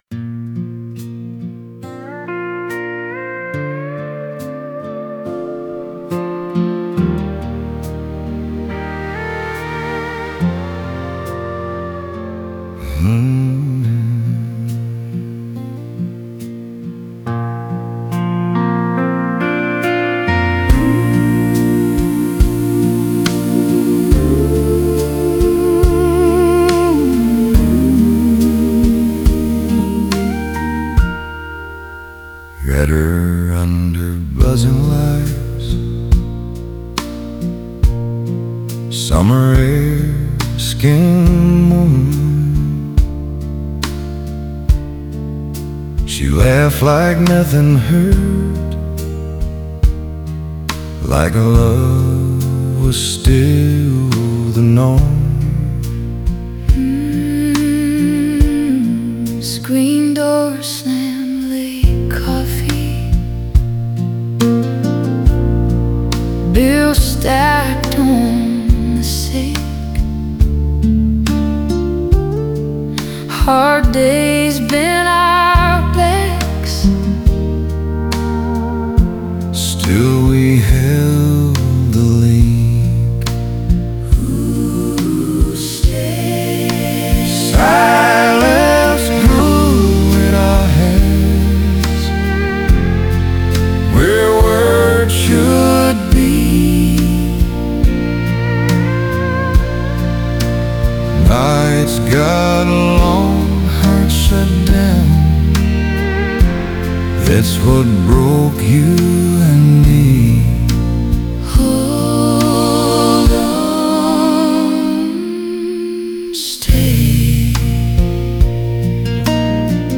オリジナル曲♪
歌詞は簡潔でリズムに乗りやすく、自然な呼吸で溶け込むハーモニーが心にしみる、深く静かな哀恋の物語を描いた構成。